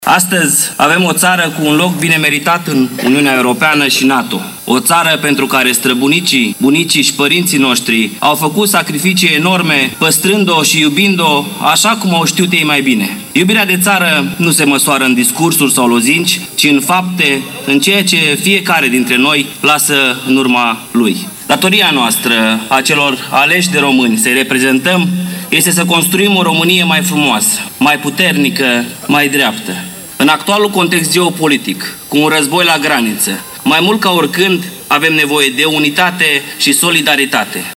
Ziua Națională a fost marcată astăzi la Suceava printr-un ceremonial militar la Monumentul Bucovina Înaripată.
Președintele Consiliului Județean Suceava GHEORGHE ȘOLDAN a accentuat importanța istorică a Zilei Naționale, evidențiind “provocările actuale pe care românii trebuie să le depășească”.